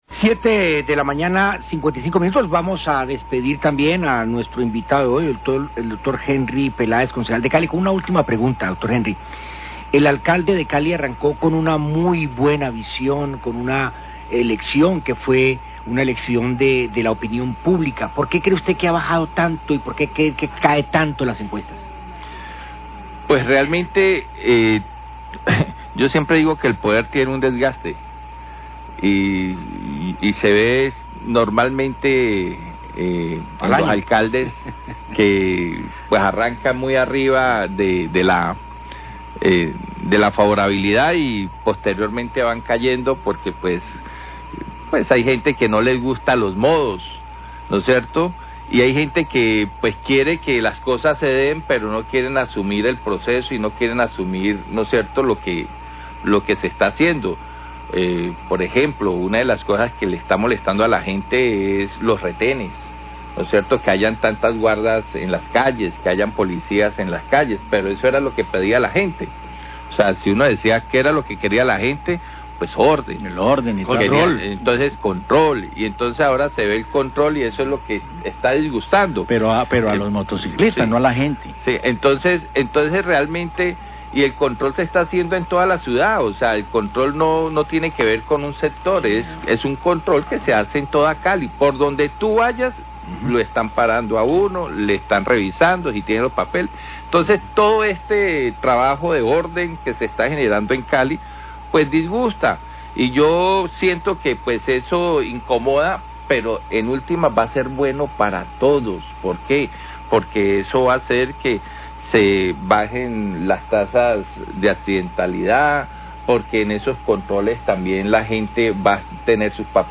Radio
Concejal Henry Peláez habla que una de las razones de la caída de la imagen del alcalde en las encuestas son los operativos de seguridad y movilidad, y señala que la realización de los operativos son algo necesario para recuperar ordenar la ciudad y que era lo que la gente estaba pidiendo desde hace mucho tiempo.